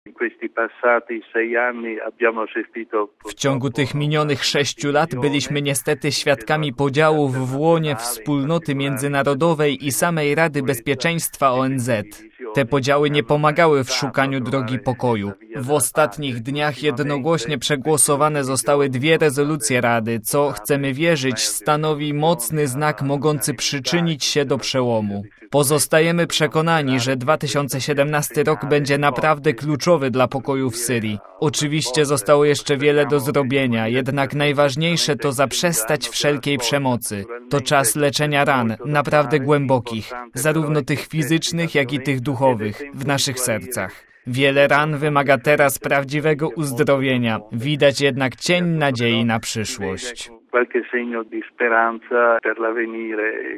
Te podziały nie pomagały w szukaniu drogi pokoju – mówi Radiu Watykańskiemu kard. Zenari.